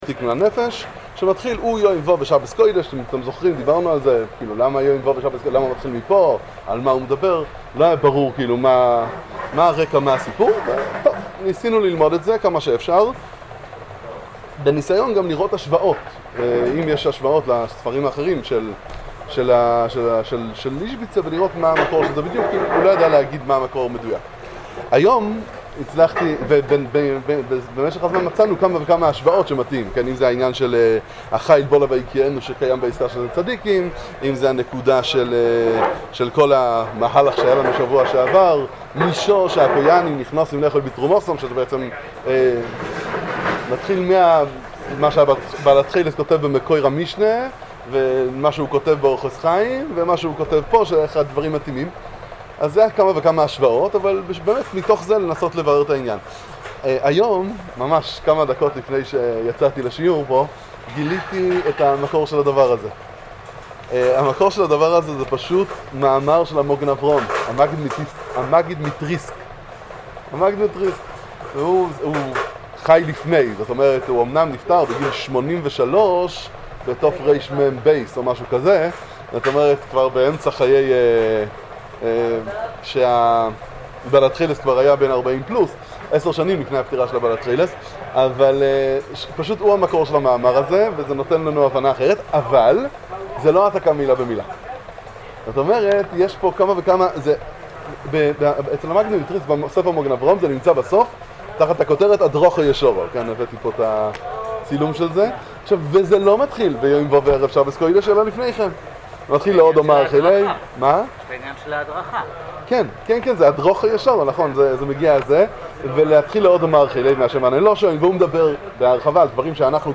שיעור בספרי איזביצא